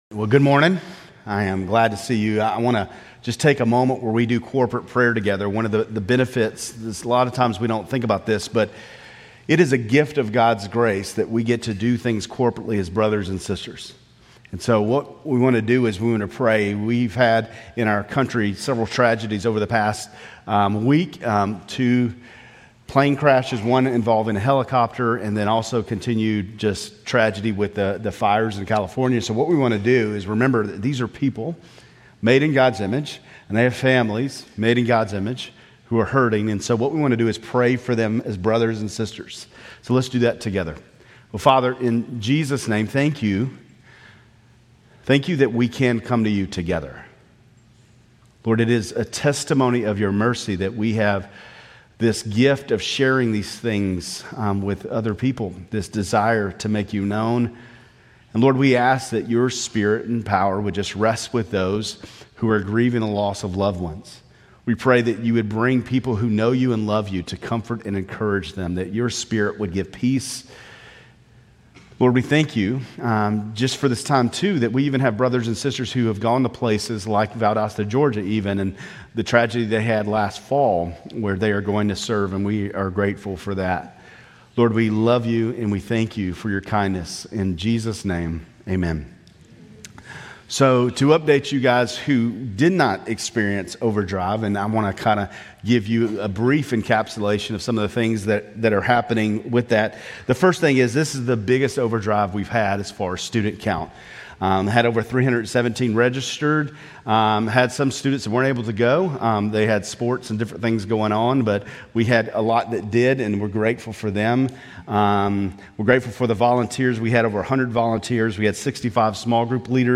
Grace Community Church Lindale Campus Sermons 2_2 Lindale Campus Feb 03 2025 | 00:30:23 1x 00:00 / 00:30:23 Subscribe Share RSS Feed Share Link Embed